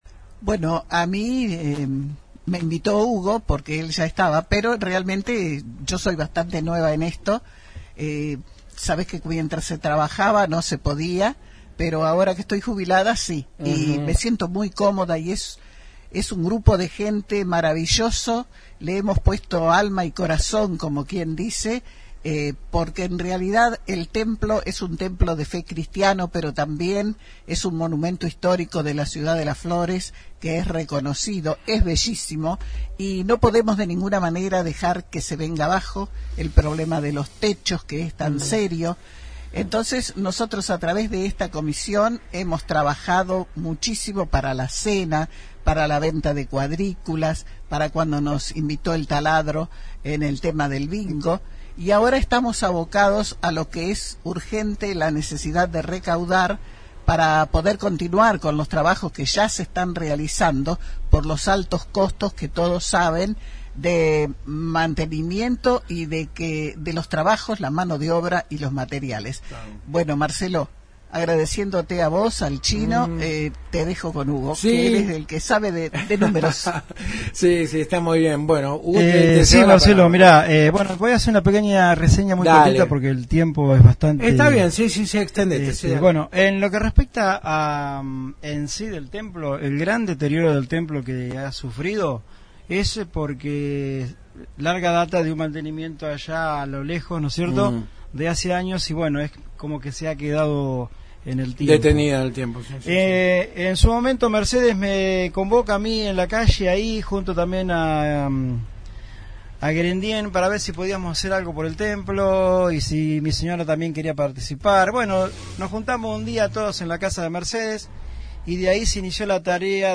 Visitaron los estudios de la 91.5